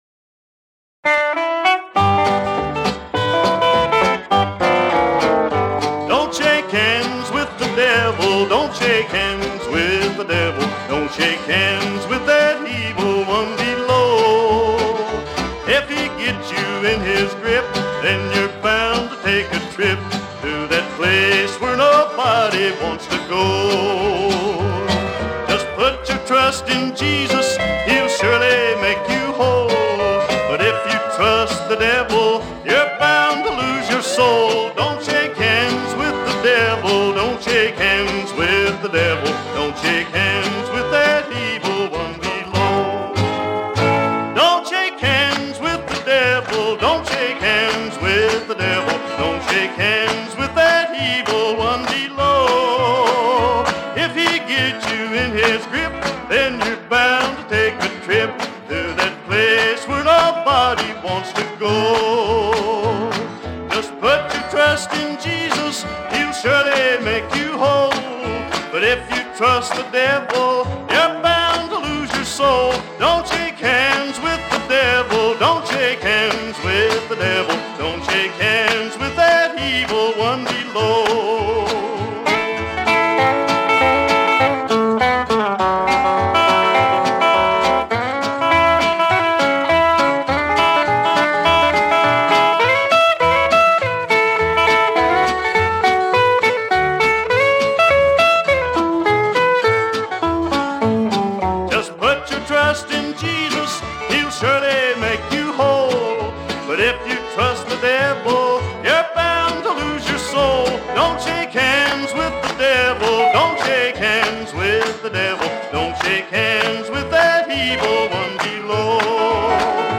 Honky-Tonk singer, songwriter and fine guitarist